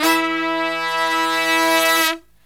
LONG HIT07-R.wav